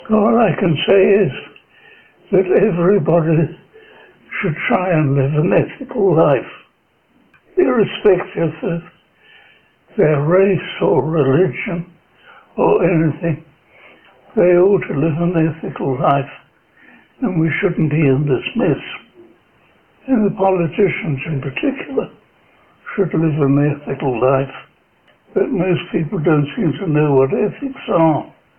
mluvil pomalu a některé otázky si musel nechat opakovat